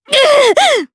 Nicky-Vox_Damage_jp_02.wav